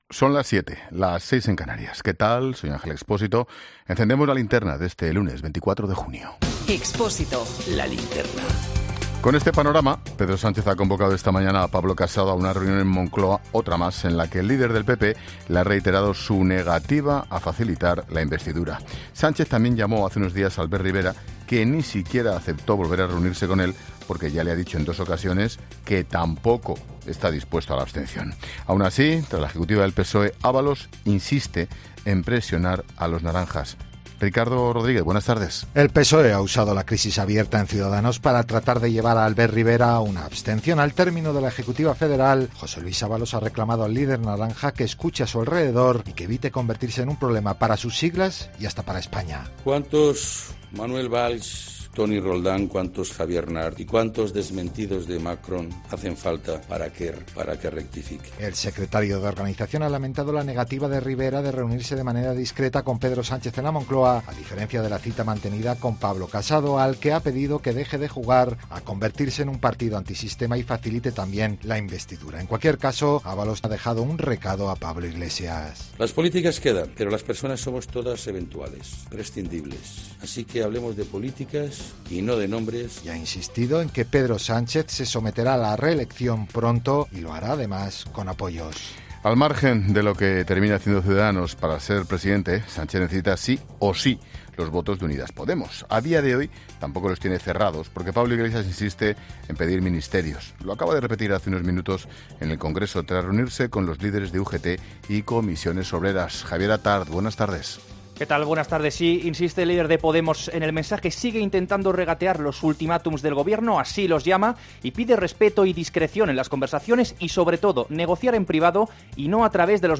Boletín de noticias COPE del 24 de junio de 2019 a las 19.00